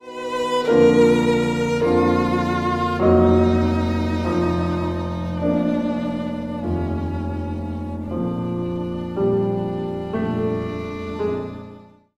mp3Bosmans, Henriëtte, Arietta for viola and piano, mm.43-45